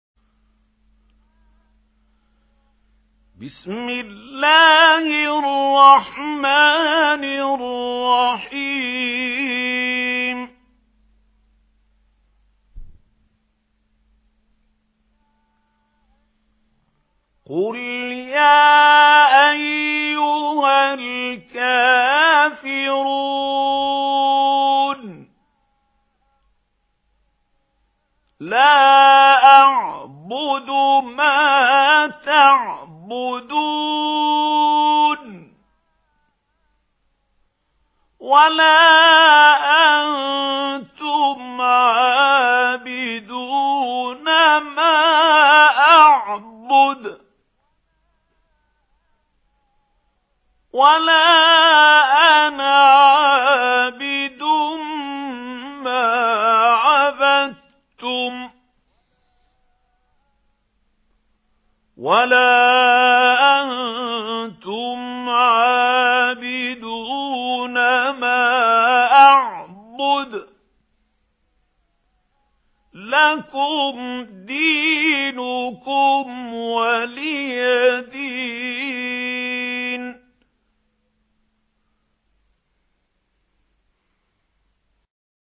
قرآن - قاری محمود خليل حصري